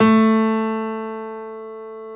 55p-pno18-A2.wav